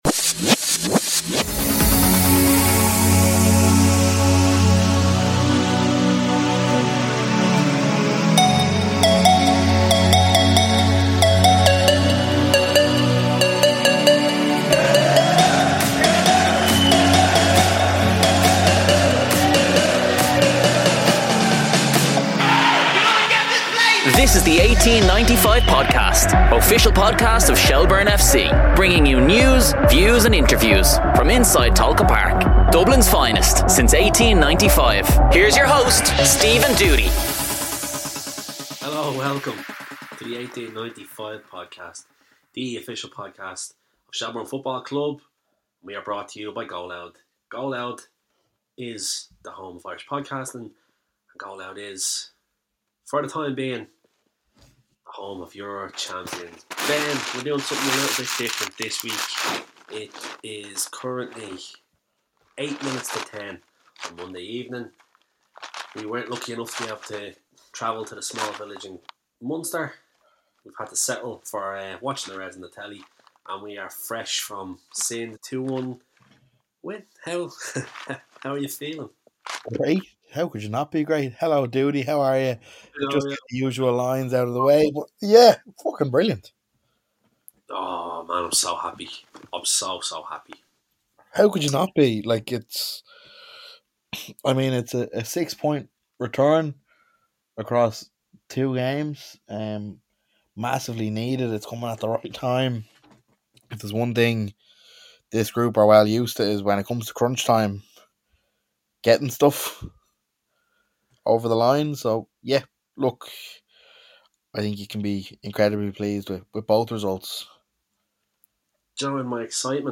Expect spirited debates, plenty of laughs, and all the insights you crave about the Reds as they gear up for their challenge against Sligo.